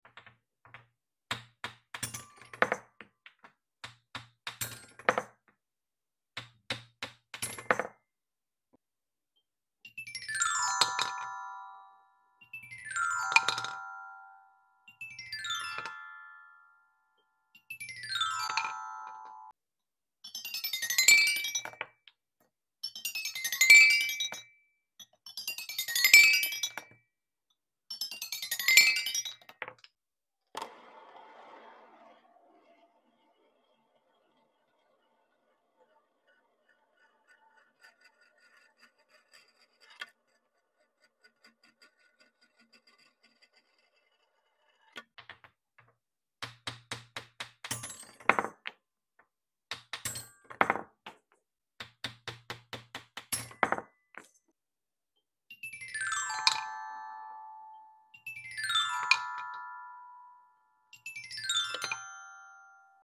Satisfying ASMR: Balls Triggering Xylophone sound effects free download